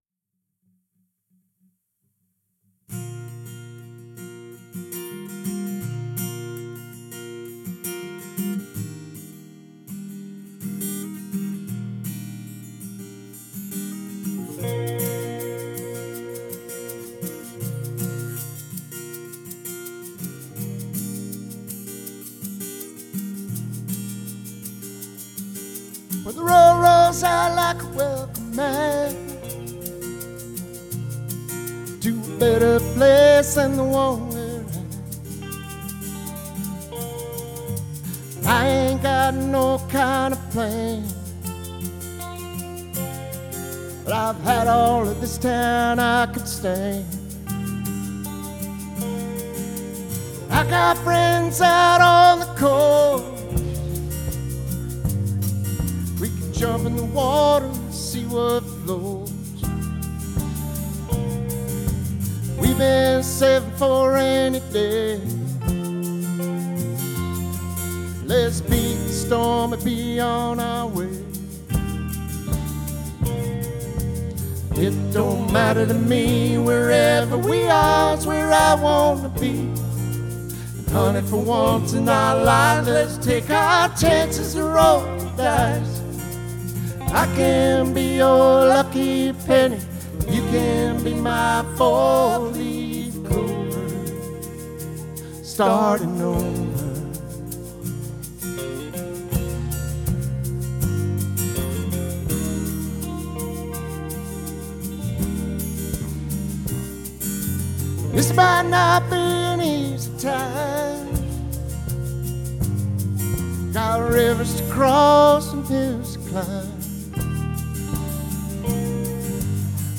Recorded Dec. 7, 2025 at the McMinnville Grange Hall
Live Audio Recordings
(All Recorded Live with no Overdubs)